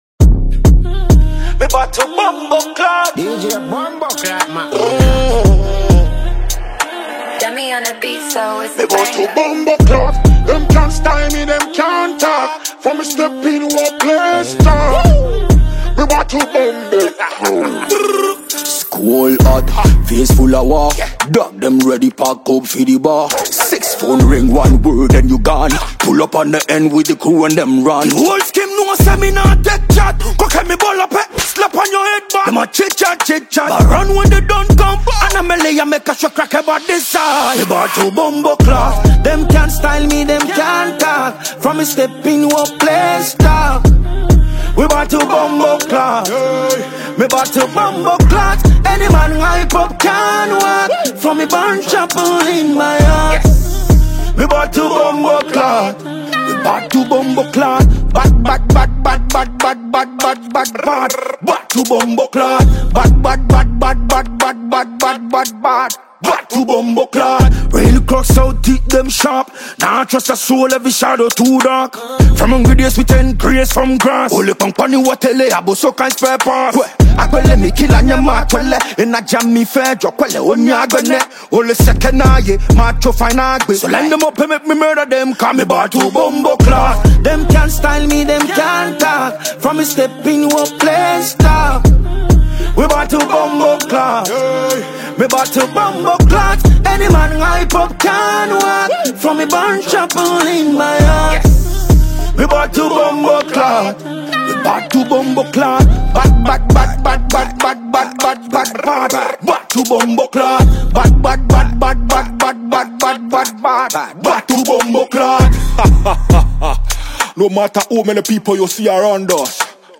vibrant vocals and confident delivery
The production is lively and polished
punchy percussion, deep basslines, and melodic synths
• Genre: Afrobeat / Dancehall